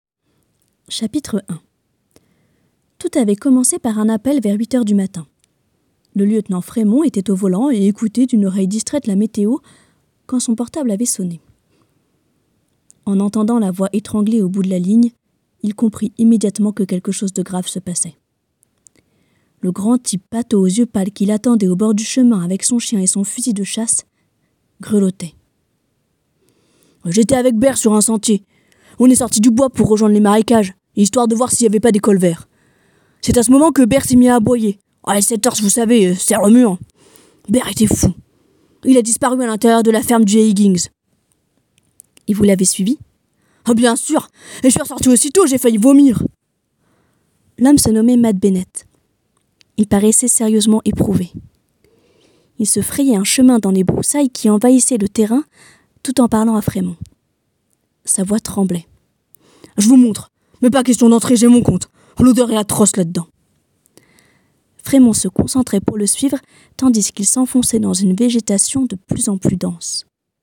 Voix off
maquette livre audio